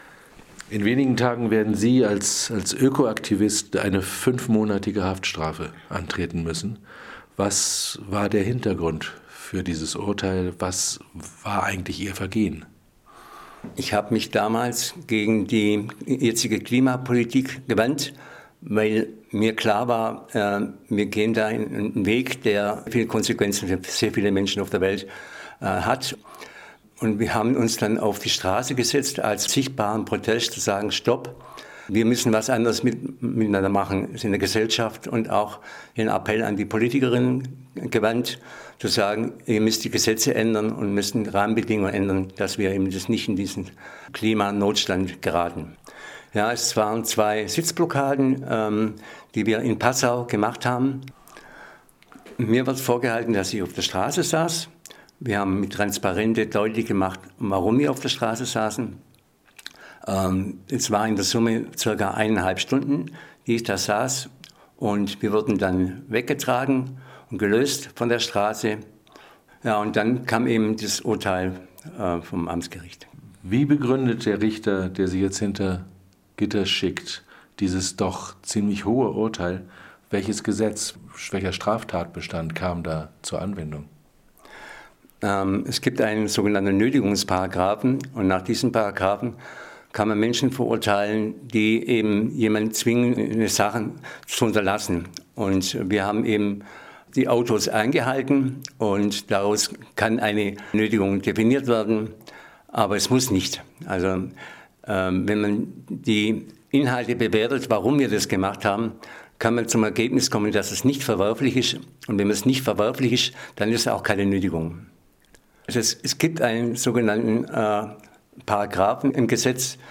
Presse/Interview